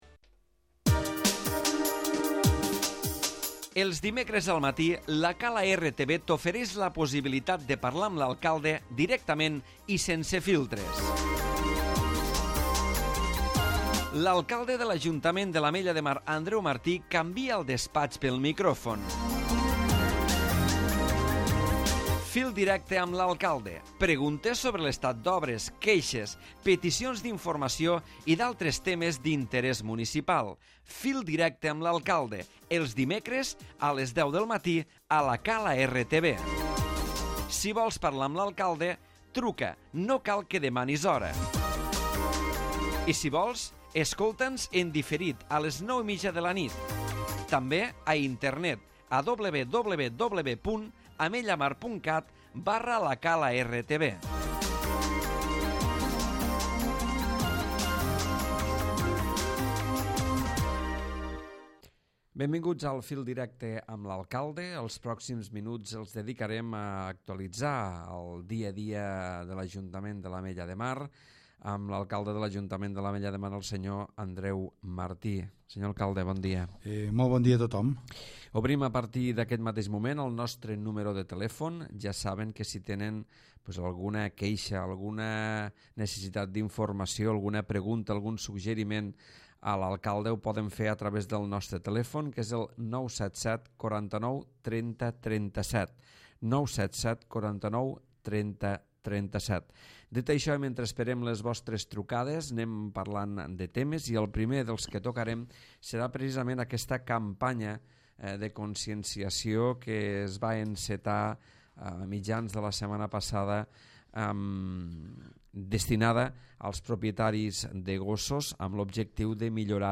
Andreu Martí, alcalde de l'Ajuntament de l'Ametlla de Mar actualitza el dia a dia de la gestió municipal i atén a les trucades dels ciutadans.